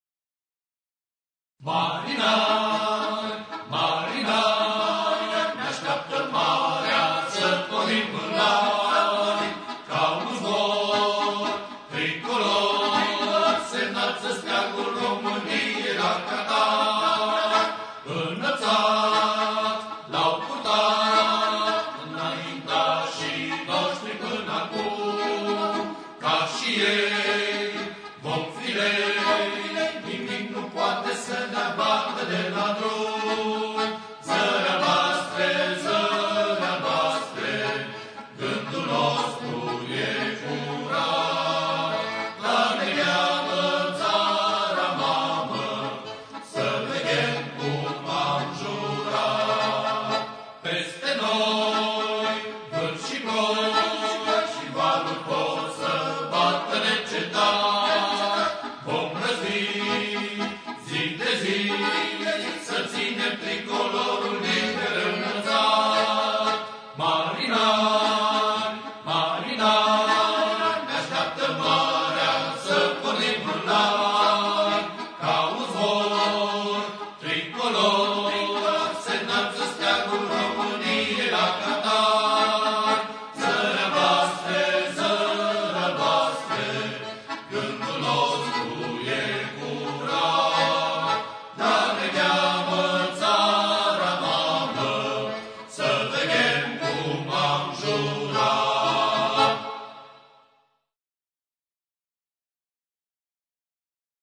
Coruri din repertoriul marinăresc
marș